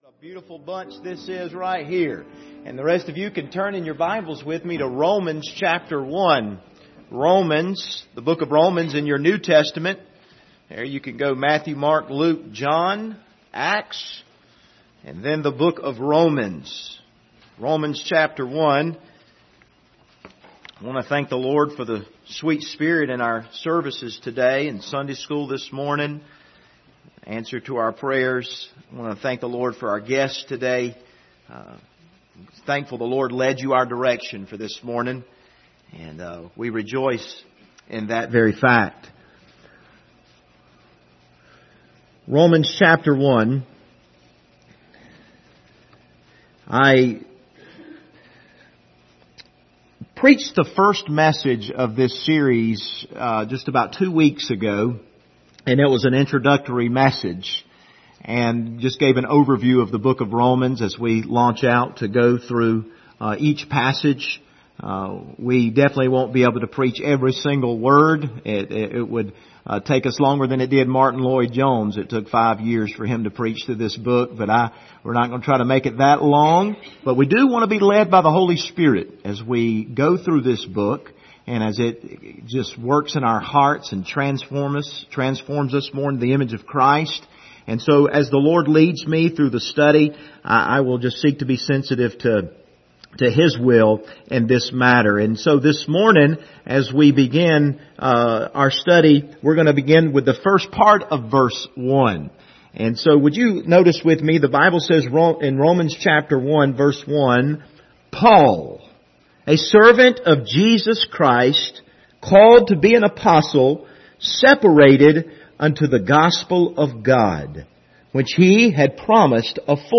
Passage: Romans 1:1 Service Type: Sunday Morning